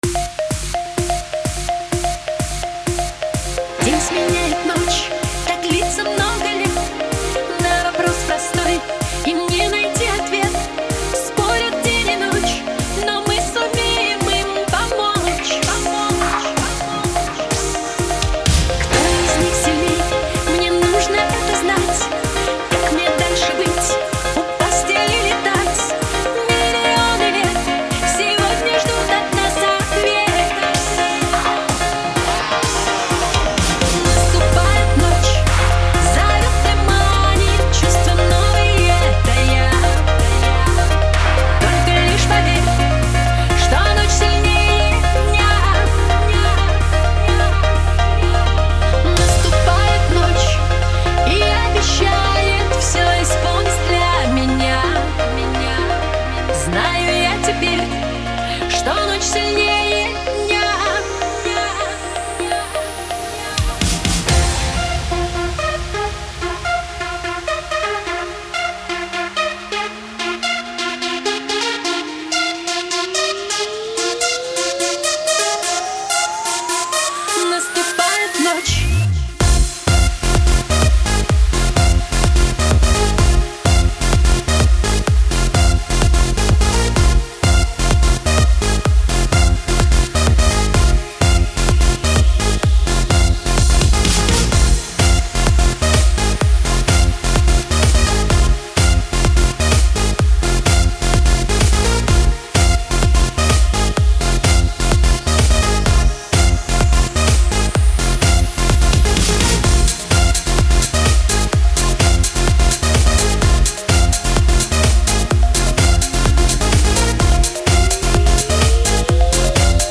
популярная музыка зимы 2012
Категория: Club - Mix